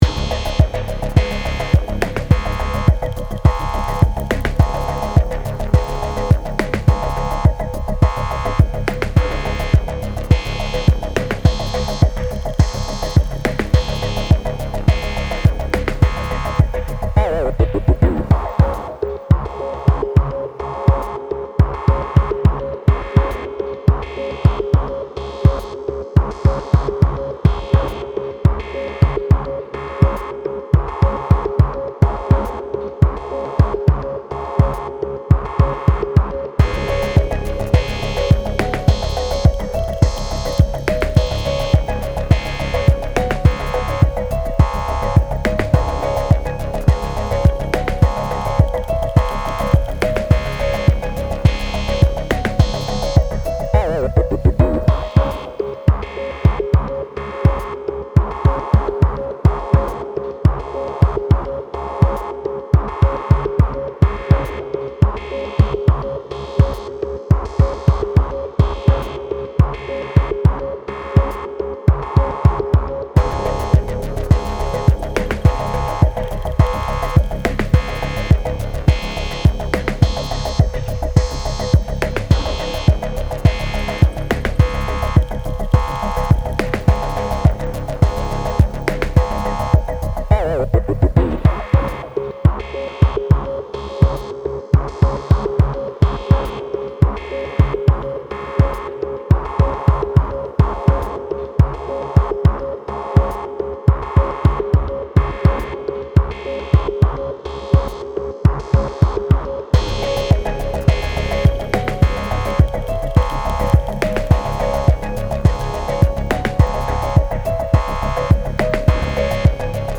危険地帯を思わせる緊迫感のあるBGM
エレクトロニック 2:28